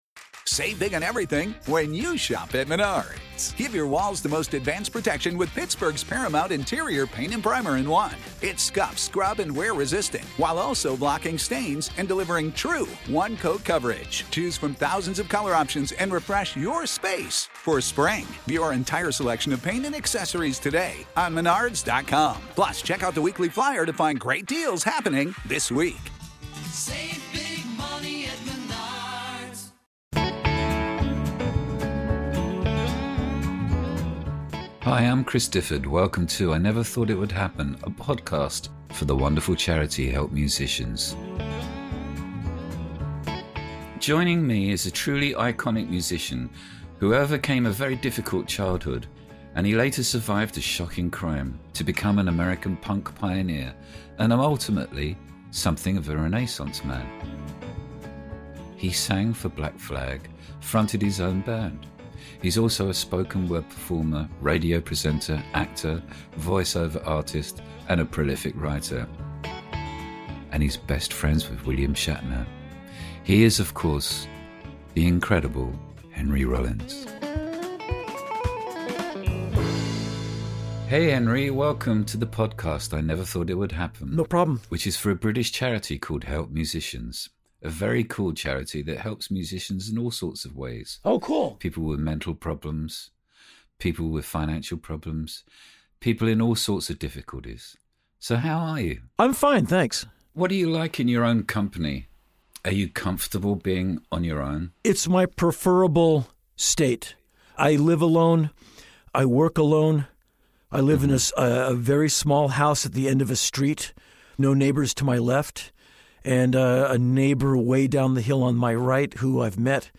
In this wide-ranging conversation, Henry talks about his remarkable career and his passion for music, something which stemmed from his difficult upbringing where music was an escape for him. But perhaps the most surprising thing to come out of this conversation is Henry’s appreciation of the classic musicals.